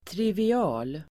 Uttal: [trivi'a:l]